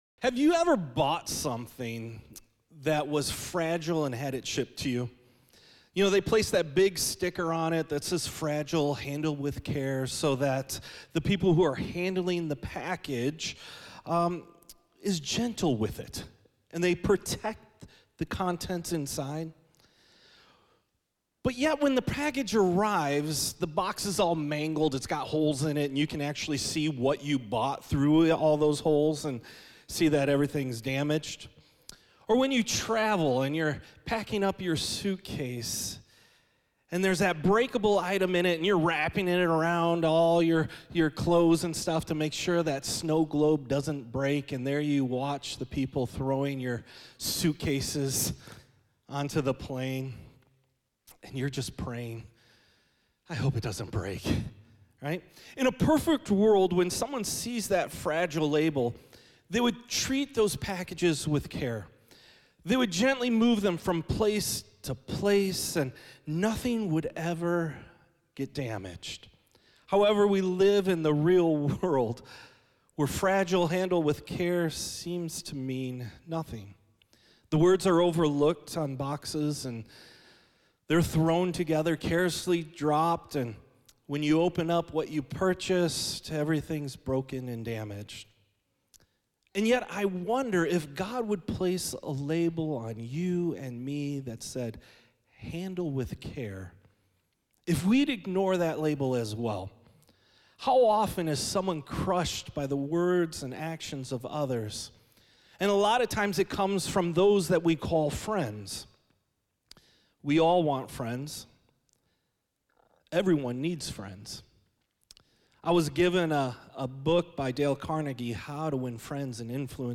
preaches a message on how to show gentleness by offering a caring hand to others.